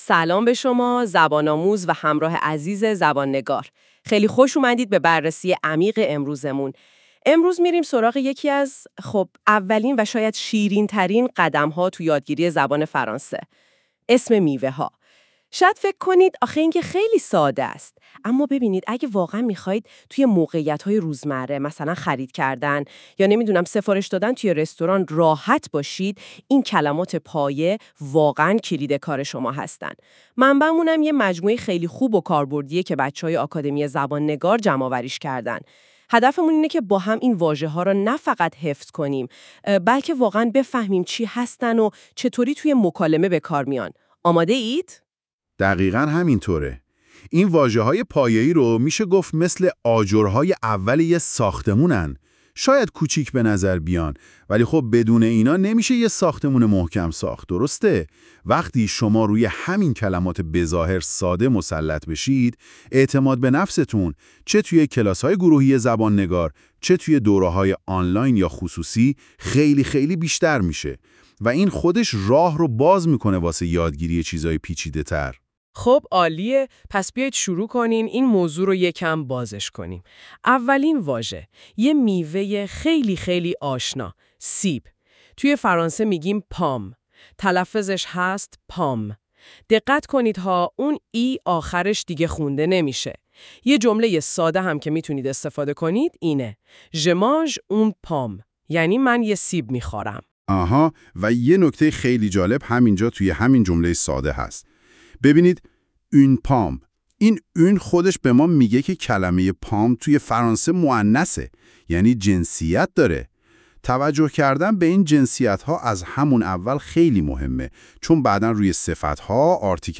واژگان-میوه_ها-در-فرانسه-با-تلفظ.wav